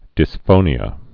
(dĭs-fōnē-ə)